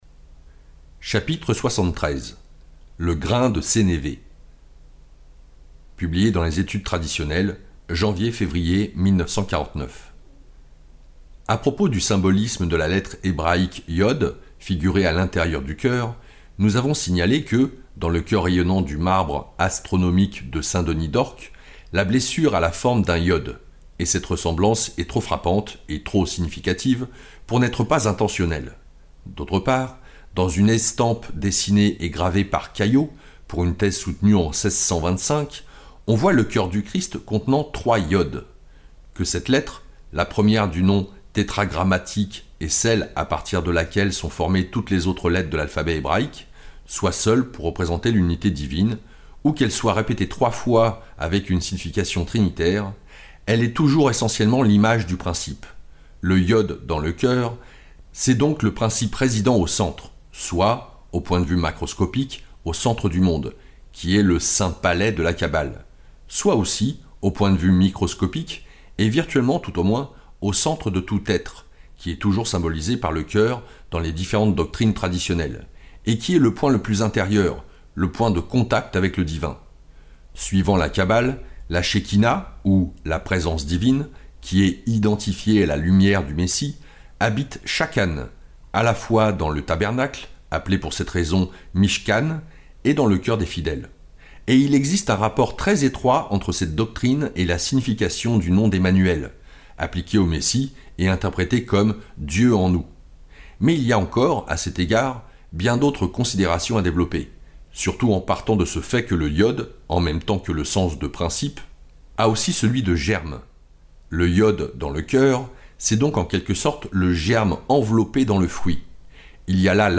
Symboles de la Science Sacrée - Livre audio - Hym Media